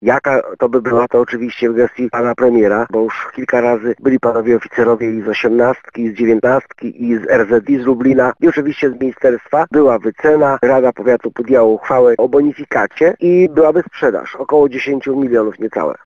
– Mówimy o zawodowej, zmechanizowanej jednostce wojskowej – potwierdza w rozmowie z Radiem Lublin starosta kraśnicki Andrzej Rolla.